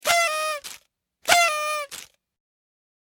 NGM_Cartoon_party.ogg